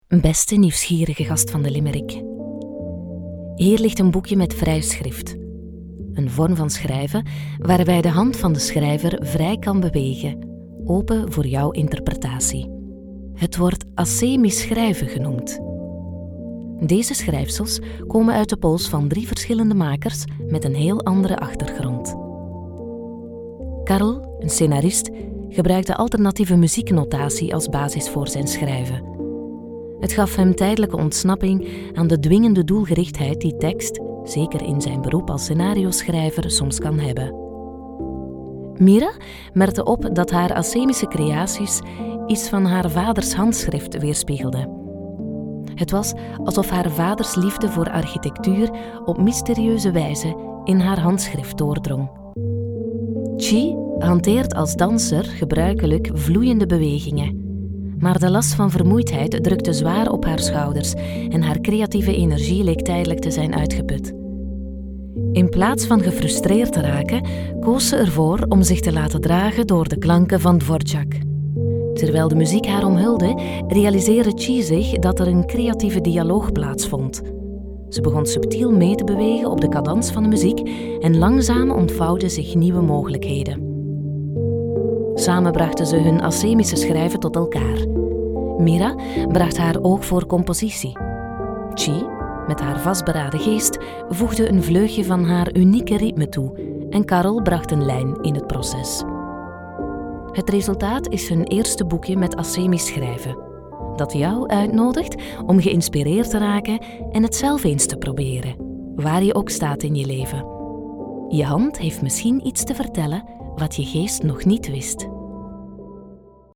Kommerziell, Natürlich, Verspielt, Zuverlässig, Warm
Audioguide